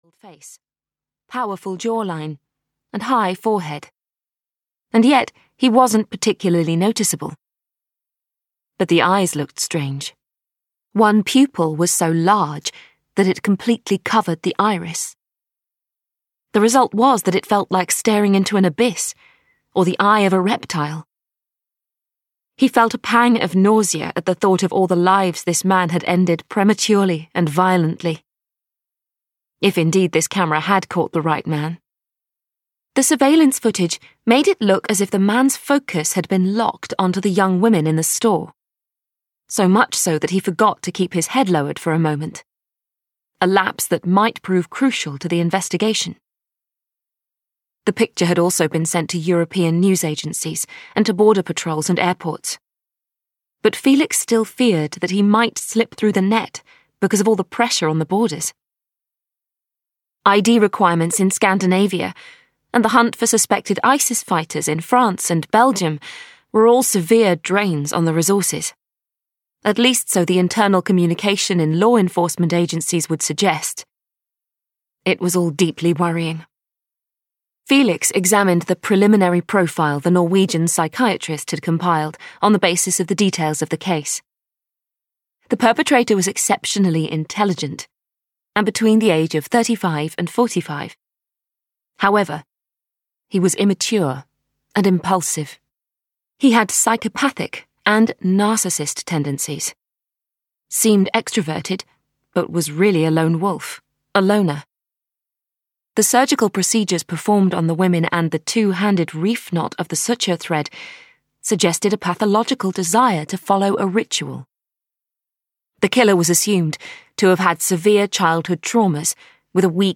Black Notice: Episode 4 (EN) audiokniha
Ukázka z knihy